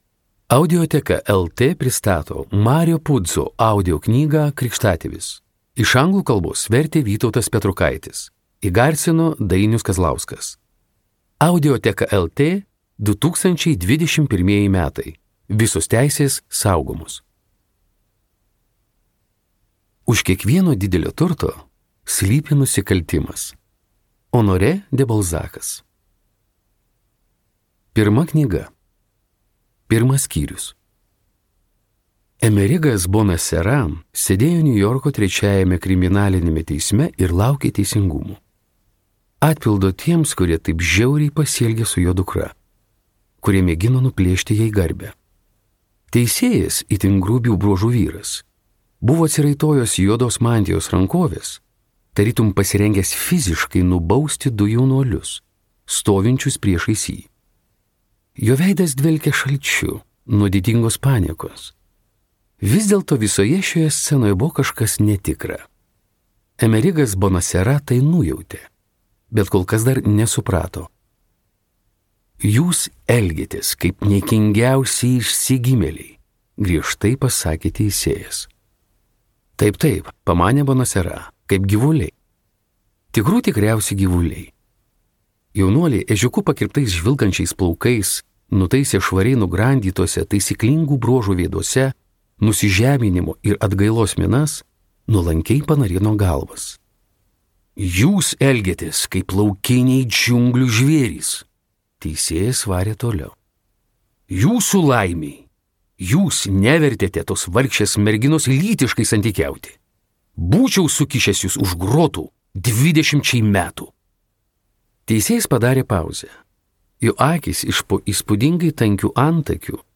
audioknyga